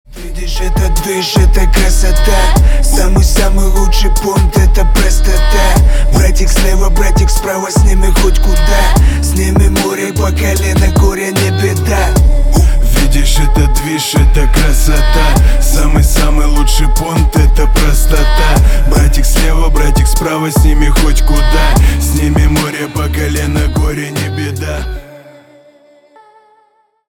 на русском гангстерские крутые